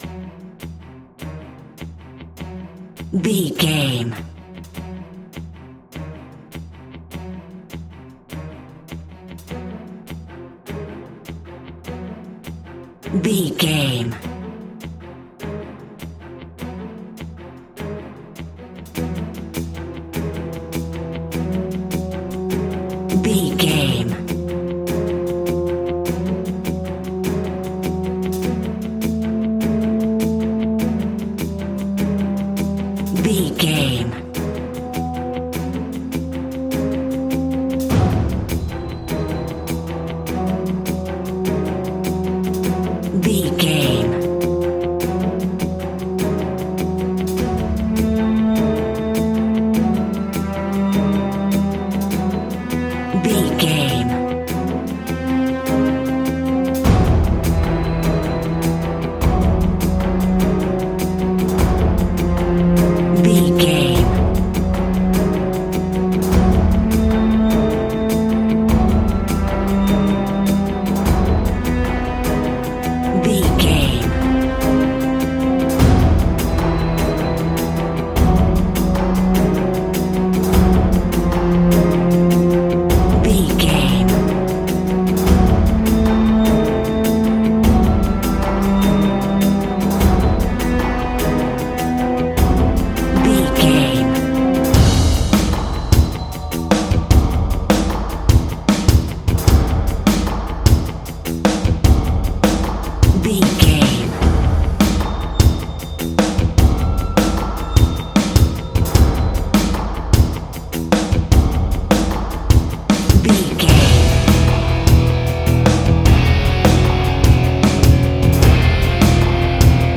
Warfare Music Theme.
In-crescendo
Aeolian/Minor
ominous
intense
strings
brass
percussion
cinematic
orchestral
fast paced
taiko drums
timpani